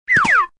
AV_jump_to_side.ogg